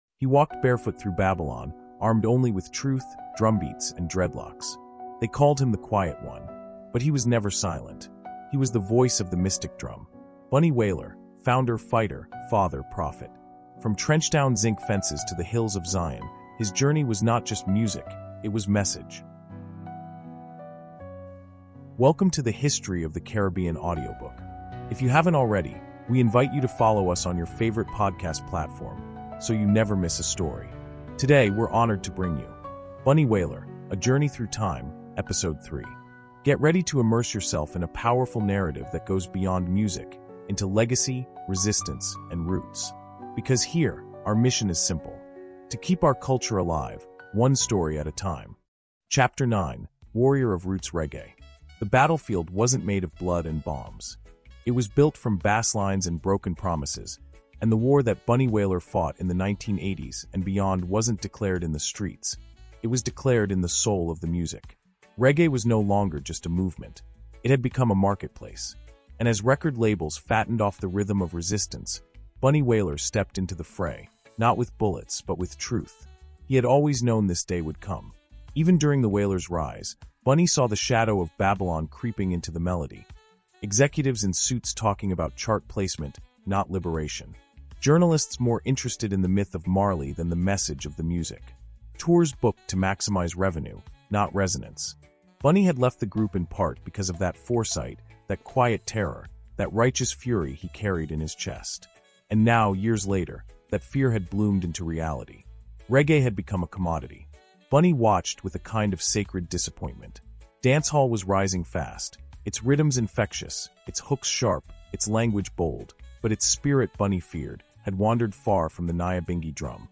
Bunny Wailer: Voice of the Mystic Drum is a powerful 16-chapter audiobook chronicling the life of reggae legend Neville “Bunny” Livingston—co-founder of The Wailers, spiritual guardian of roots reggae, and the last lion of a cultural revolution. From Nine Mile to Trenchtown, from spiritual exile to global prophecy, this immersive storytelling journey reveals the heart, fire, and message of a man who never bent to Babylon.